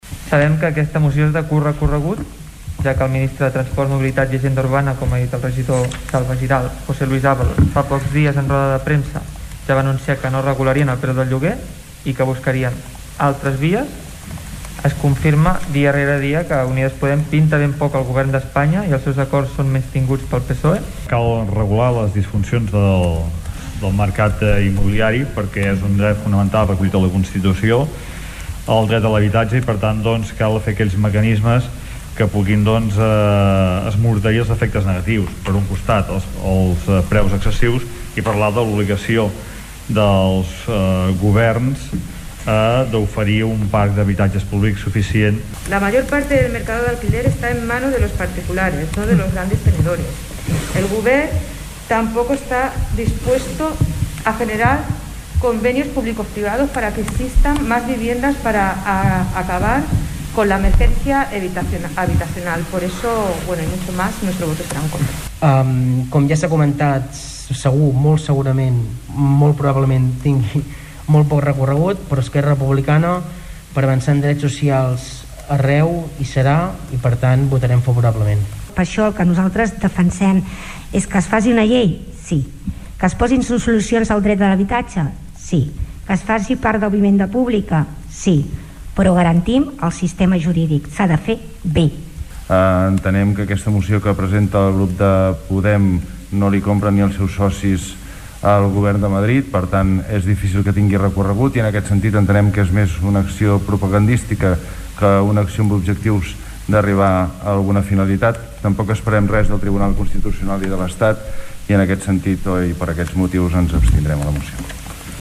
Els grups municipals van valorar de forma diferent la moció, però en tots els casos van coincidir el poc recorregut que té la moció davant les recents declaracions de José Luis Ábalos. Escoltem Oriol Serra (CUP), Xavier Pla (+ Tordera),Miriam Rocabruna (ciutadans), Jordi Romaguera (ERC), Toñi Garcia (PSC) i Josep Llorens (Junts).